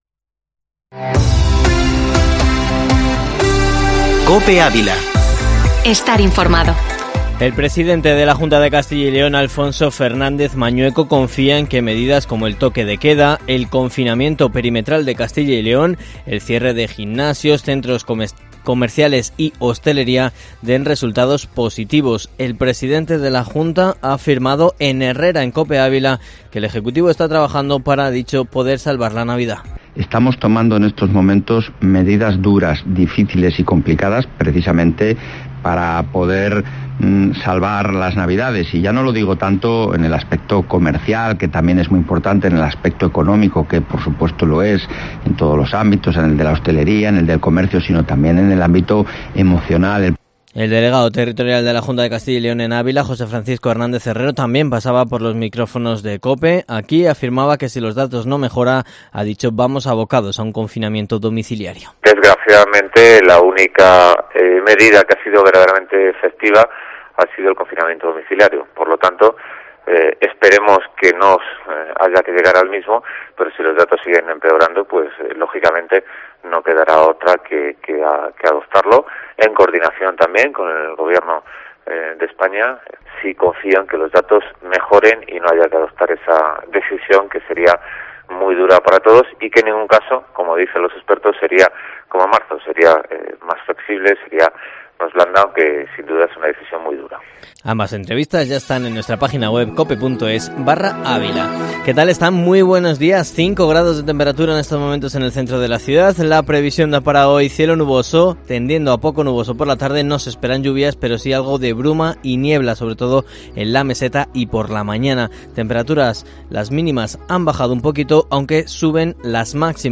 Informativo matinal Herrera en COPE Ávila 10/11/2020